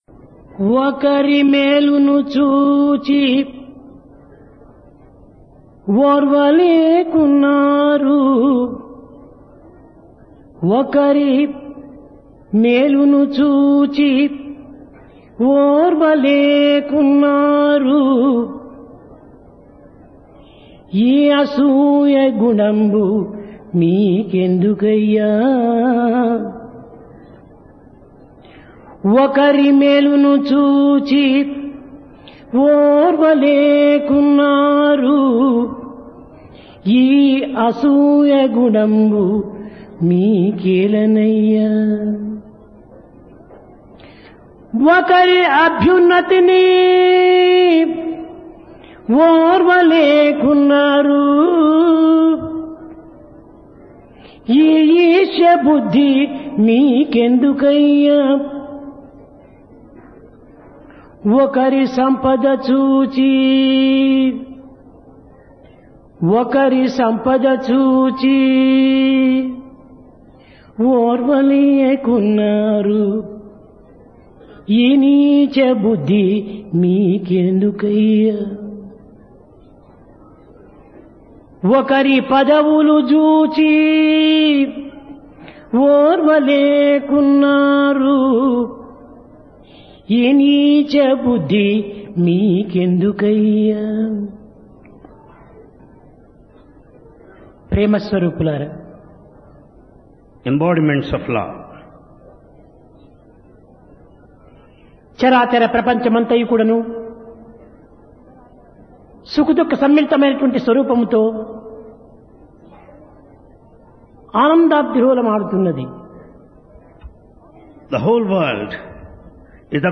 Divine Discourse | Sri Sathya Sai Speaks
Place Prasanthi Nilayam Occasion Dasara, Vijayadasami